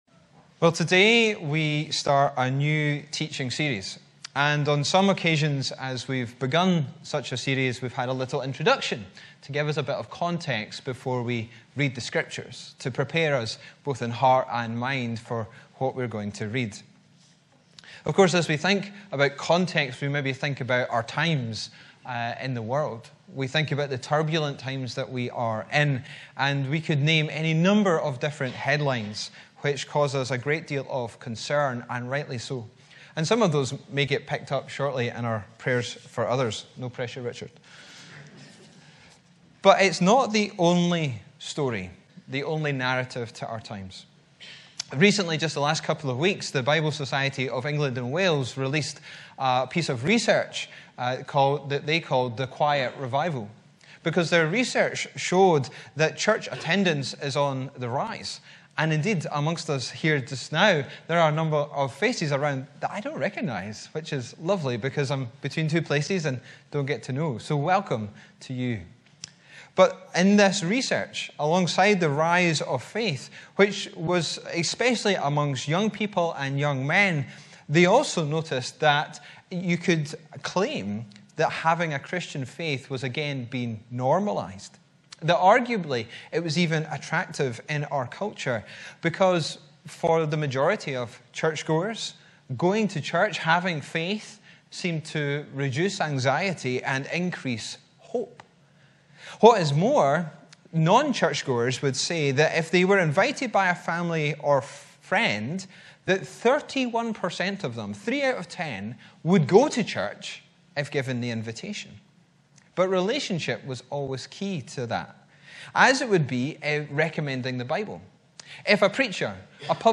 Sermons in this Series
Location: Upper Braes Parish Church – Brightons Ministry Centre